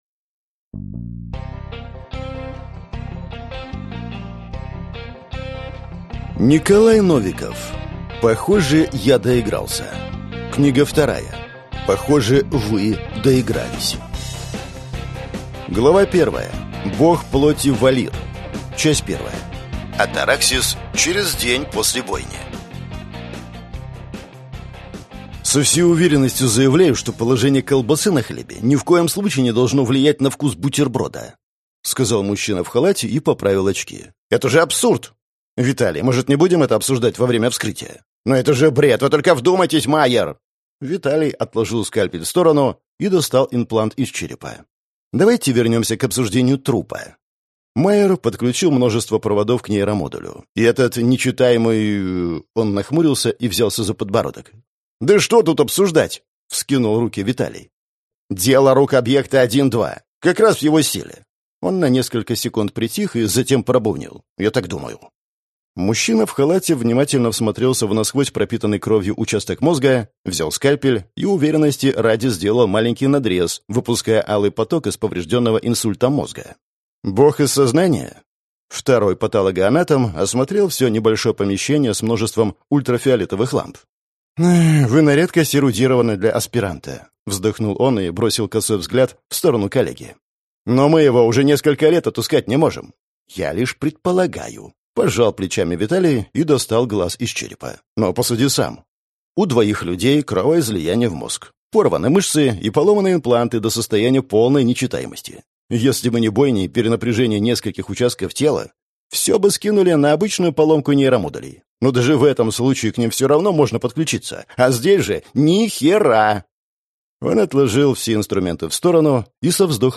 Аудиокнига Похоже, я доигрался. Книга 2. Похоже, вы доигрались | Библиотека аудиокниг